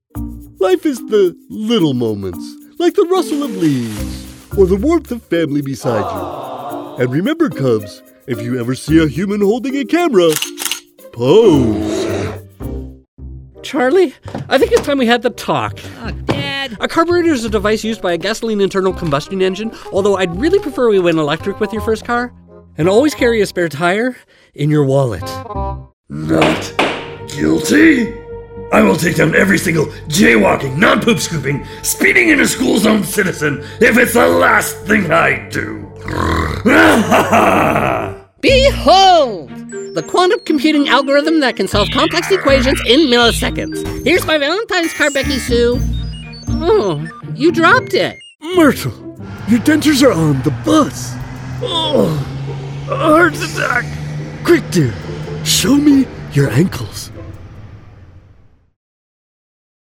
Animación
Aporto claridad, calidez y autenticidad a cada proyecto, adaptando mi voz cómodamente entre un tono conversacional, autoritario o enfocado en personajes según las necesidades del cliente.
BarítonoAltoBajo